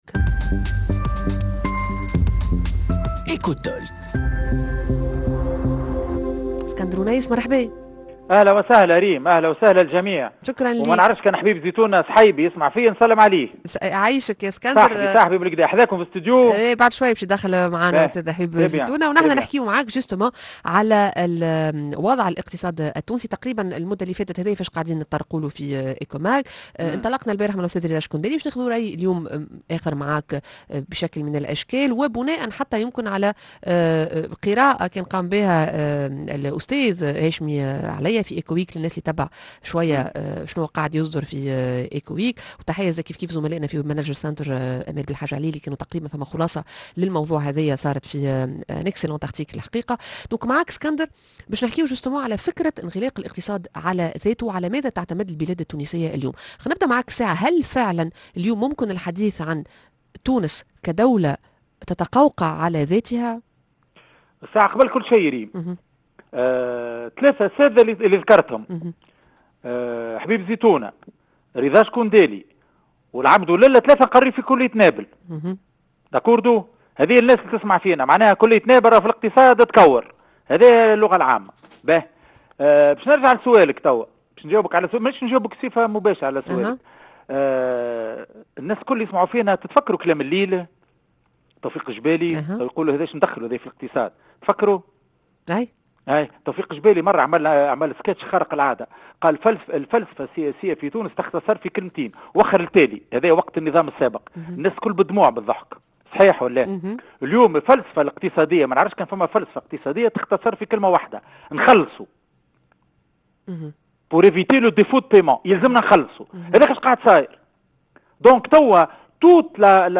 -فيتش ريتينغ ستصدر عشية اليوم تصنيف تونس وكل السيناريوهات متوقعة التفاصيل مع ضيفنا عبر الهاتف